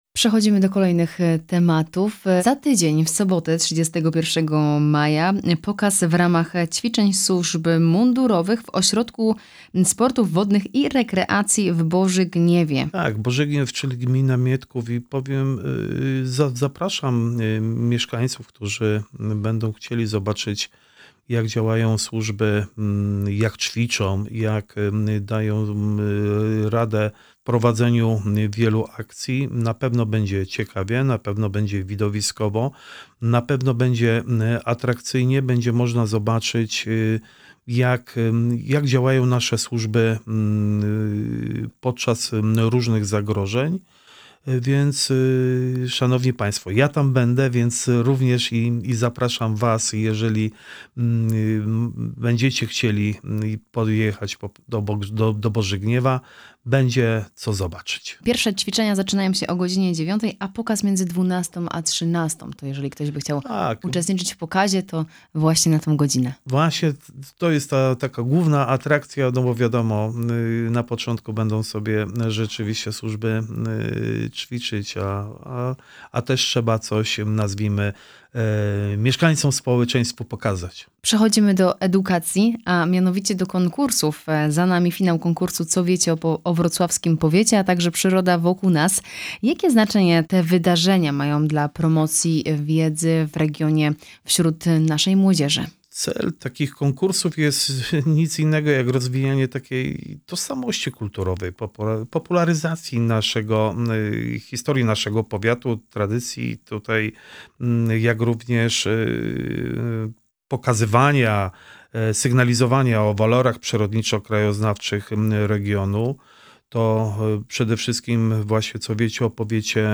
W rozmowie poruszymy również temat działań edukacyjnych oraz prozdrowotnych. Gościem Radia Rodzina był Włodzimierz Chlebosz, Starosta Powiatu Wrocławskiego.
Cała rozmowa: